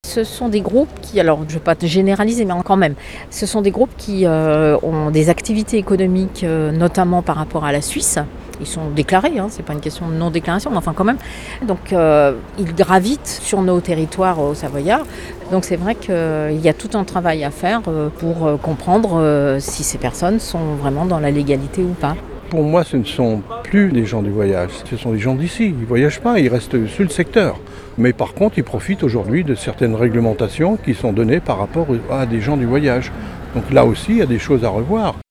Vendredi 5 septembre, une trentaine d'entre eux ont manifesté devant la préfecture d'Annecy pour réclamer plus de fermeté contre les installations illégales des gens du voyage.